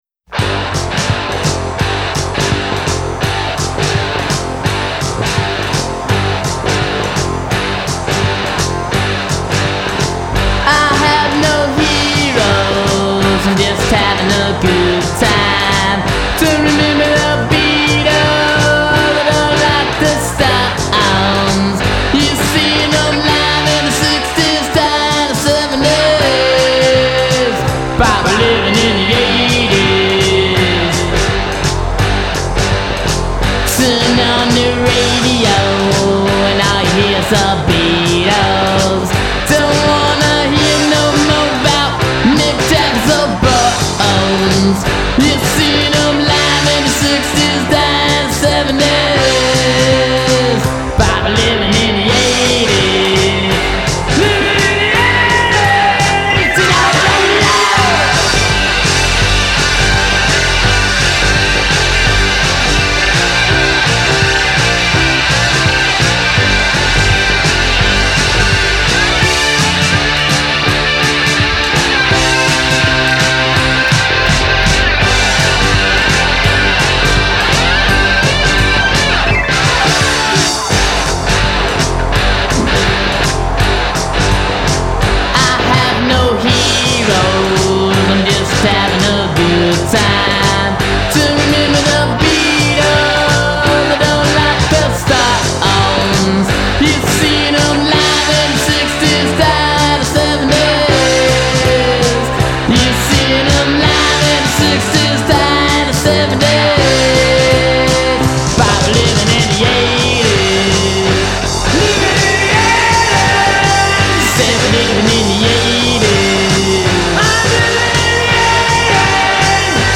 1980s hardcore punk